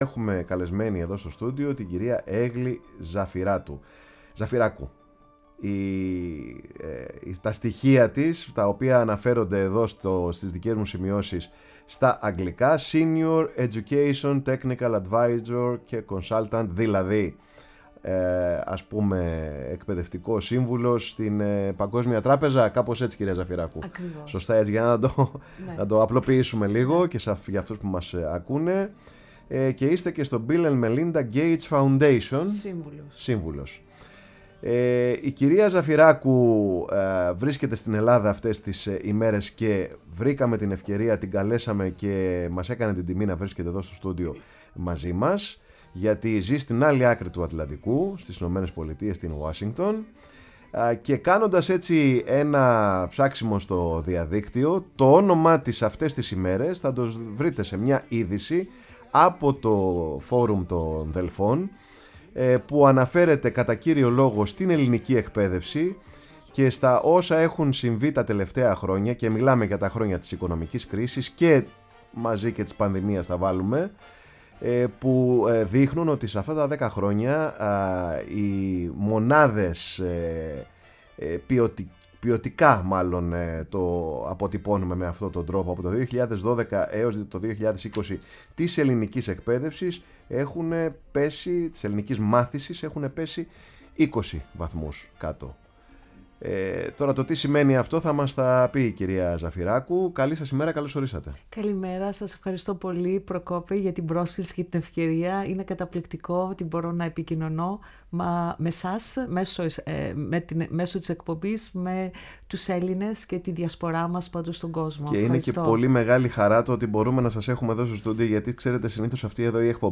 φιλοξενήθηκε σήμερα στο στούντιο της Φωνής της Ελλάδας